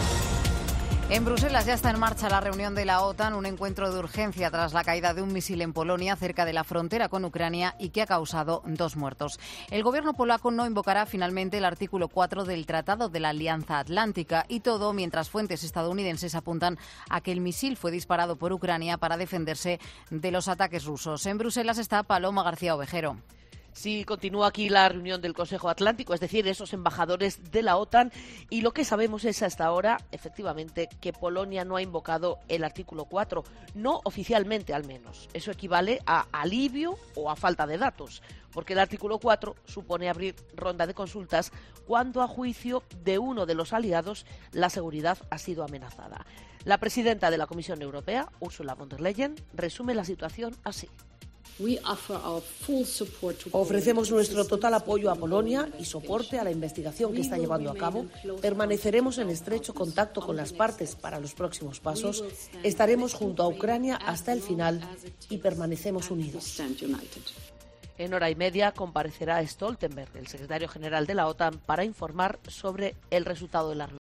Reunión de emergencia de la OTAN para analizar lo ocurrido en Polonia. Crónica